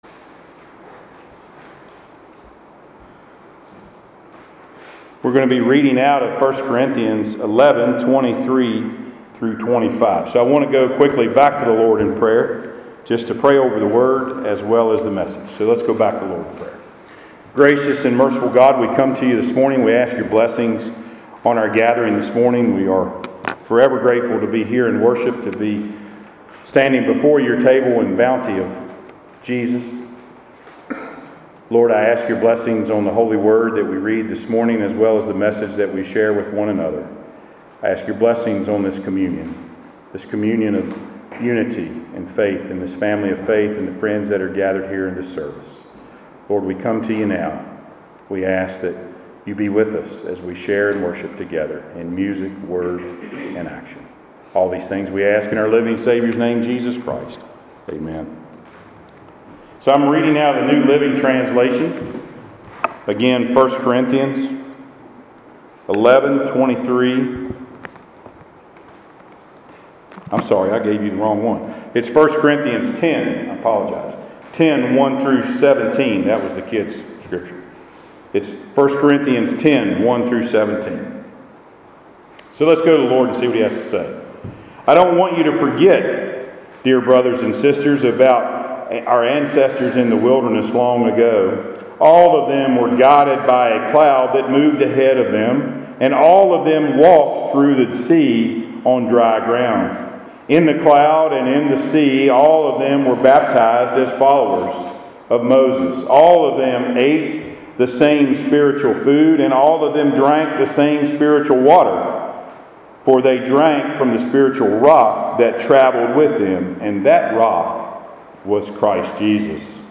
Passage: 1 Corinthians 10:1-17 Service Type: Sunday Worship